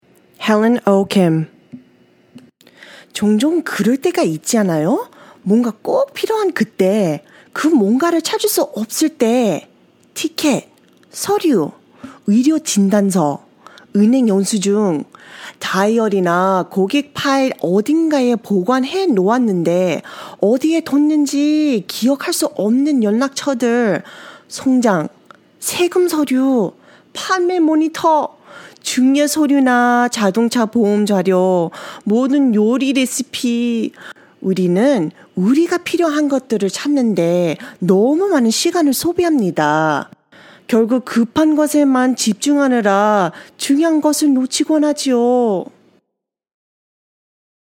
KO HOK EL 01 eLearning/Training Female Korean